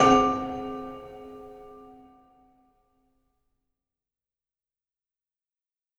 Percussion Hits
Celesta-Hit-1.wav